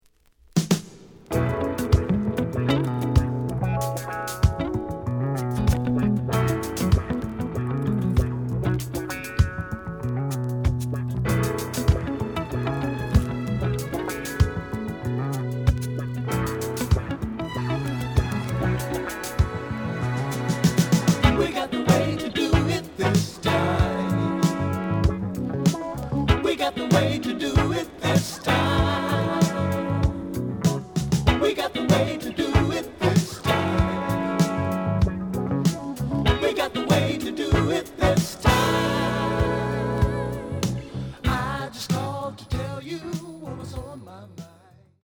The audio sample is recorded from the actual item.
●Format: 7 inch
●Genre: Soul, 80's / 90's Soul